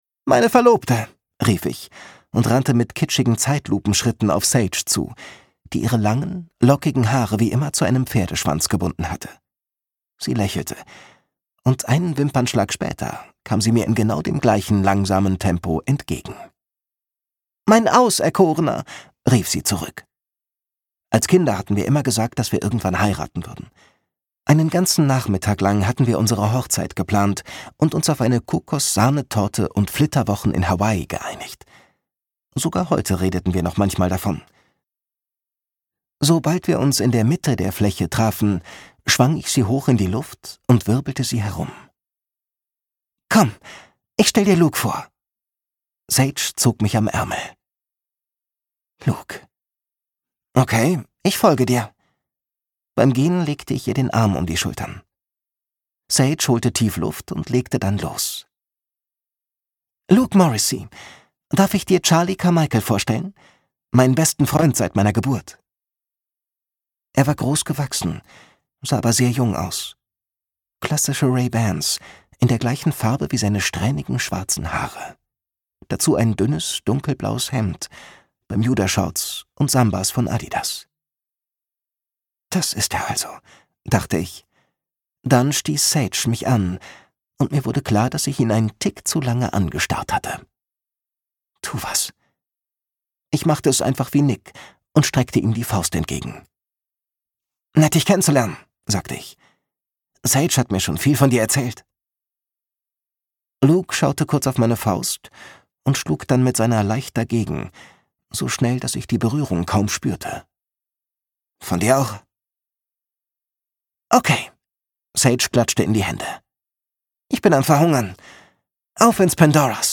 2025 Argon Hörbuch